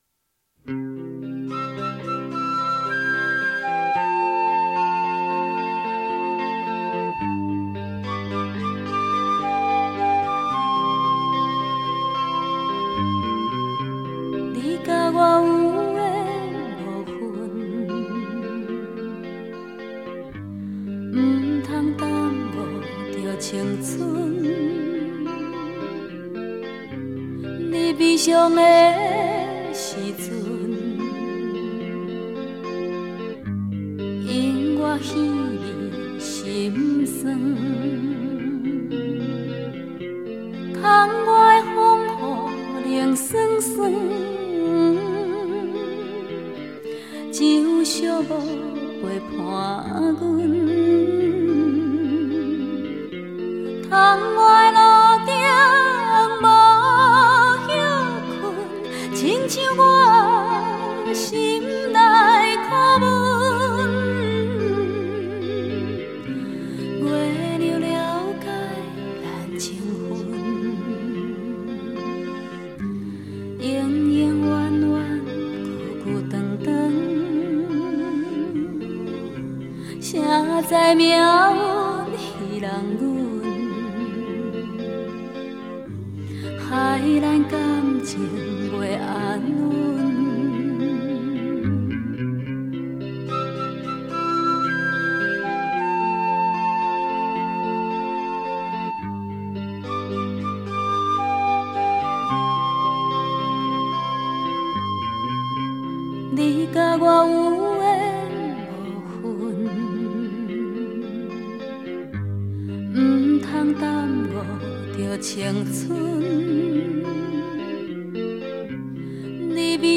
【台語歌后】